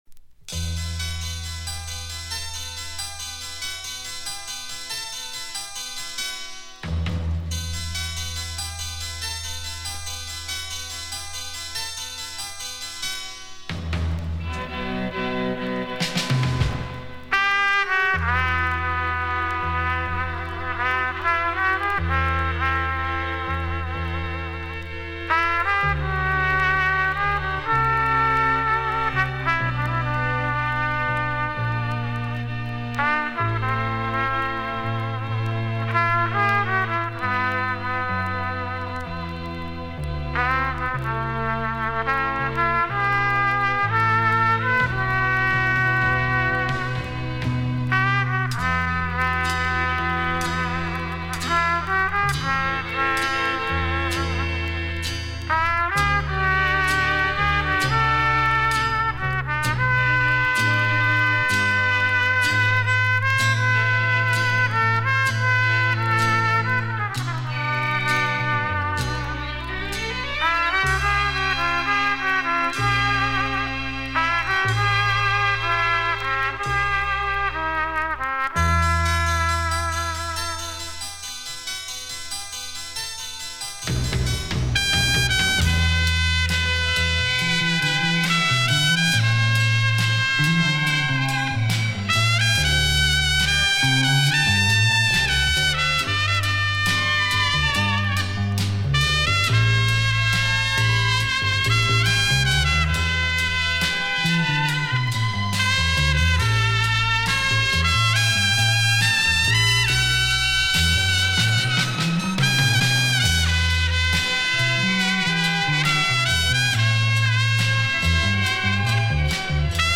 Соло на трубе.